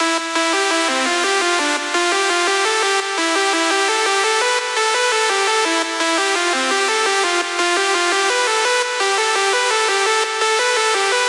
Powerstomp 2 Bass (170 BPM/ C Major)
描述：使用Massive创建并使用第三方效果处理的powerstomp bass。
标签： 循环 性交 C大调 英国的性交 音乐 狂欢 Powerstomp EDM 170-BPM 舞蹈 样品
声道立体声